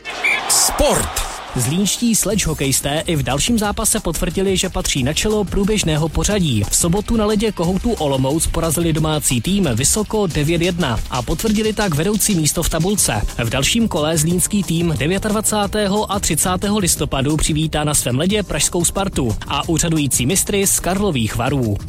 Zprávy z rádia Kiss Publikum k poslechu